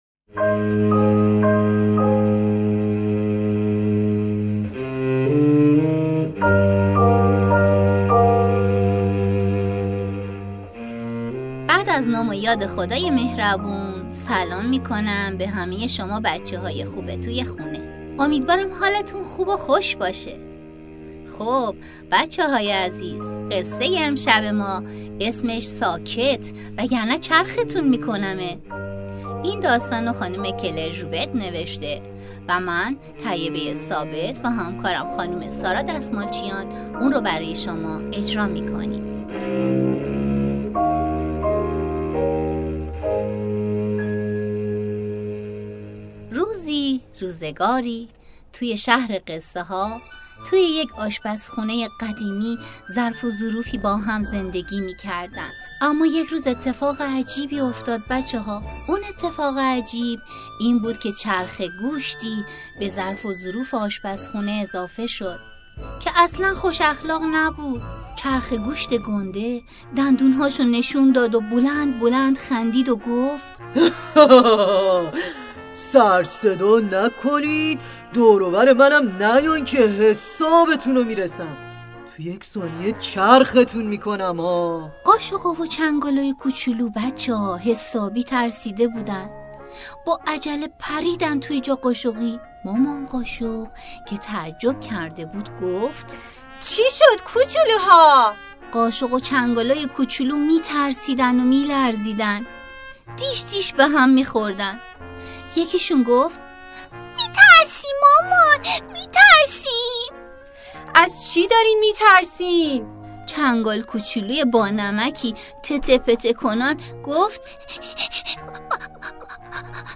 پادکست داستانی | «ساکت؛ وگرنه چرختون می‌کنم!»
داستان